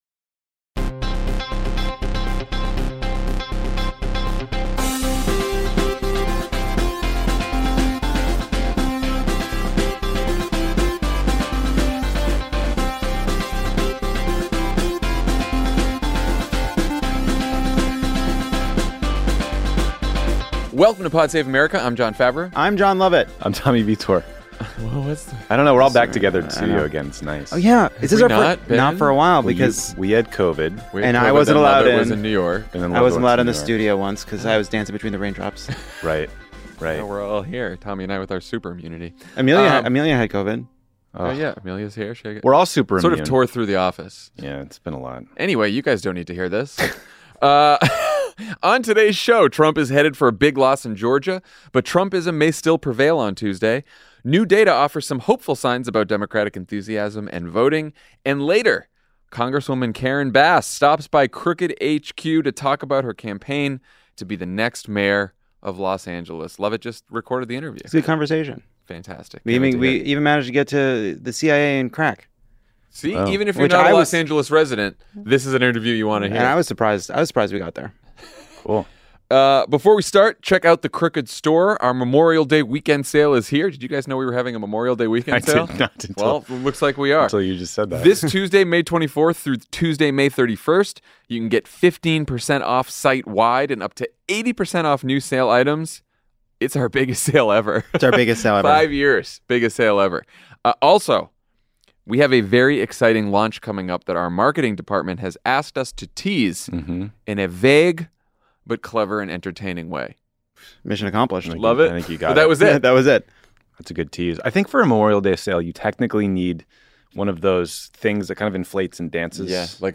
Texas, Alabama, Arkansas, Minnesota, and Georgia hold primaries this week, including the biggest race with possibly the most predictable outcome: Georgia Governor Brian Kemp versus Senator David Perdue, and Representative Karen Bass stops by the studio to talk about how she'll tackle crime and housing as mayor of Los Angeles.